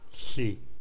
Voiceless dental and alveolar lateral fricatives